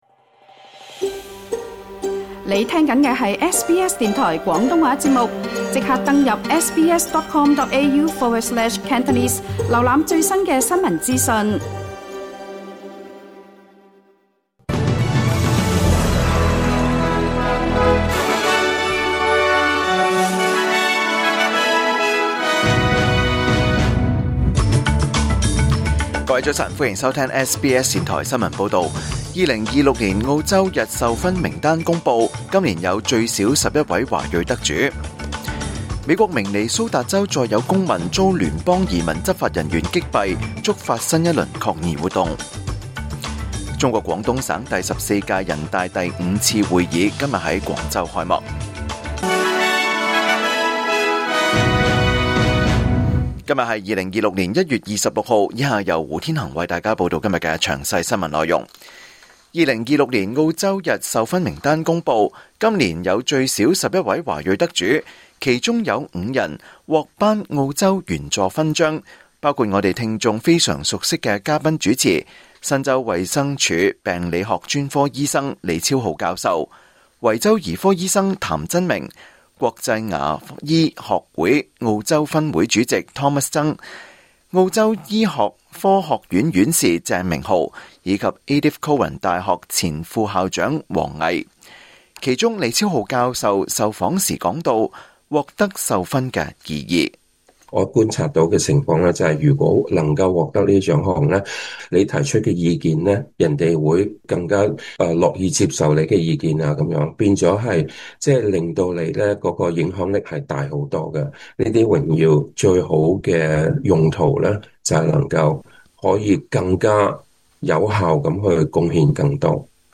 2026年1月26日SBS廣東話節目九點半新聞報道。